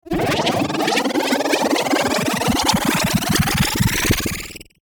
mech_attach.ogg